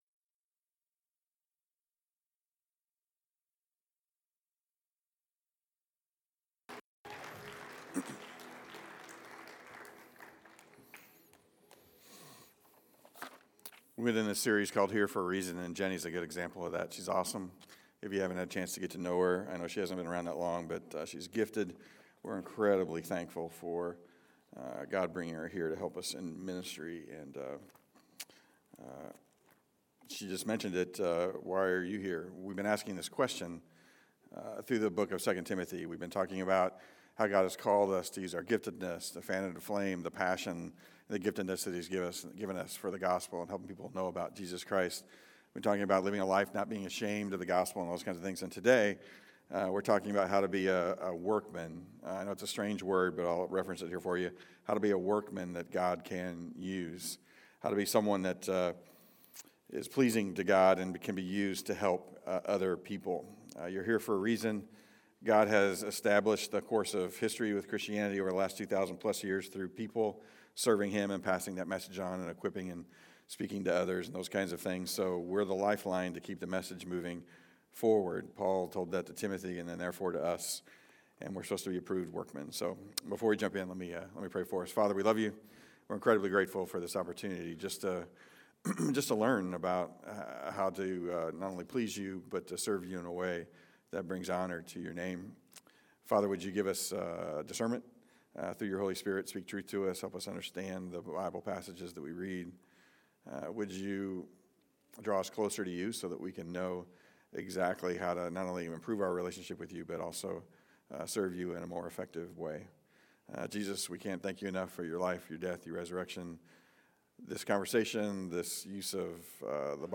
This sermon on 2 Timothy 2:14-26 stresses that our words and actions are the building blocks that influence others.